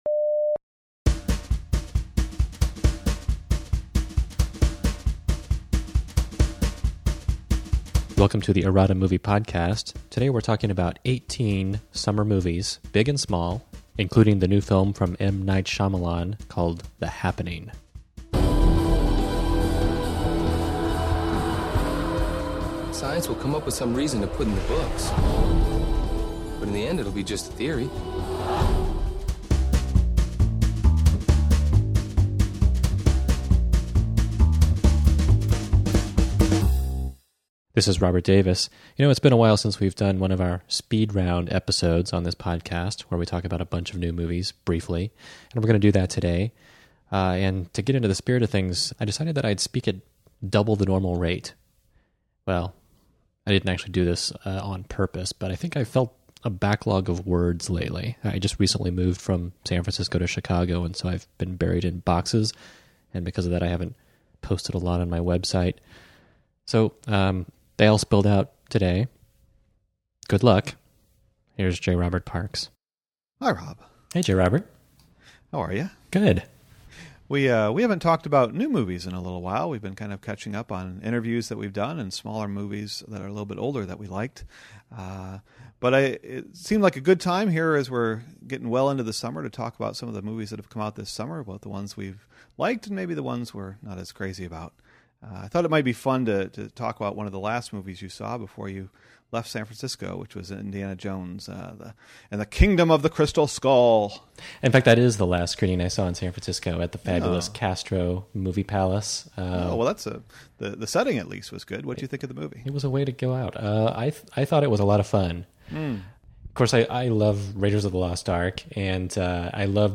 Eighteen full and considered film reviews, conceived not at all hastily but presented as though they were, an illusion accomplished not with mirrors but by speaking at double the accepted conversational rate.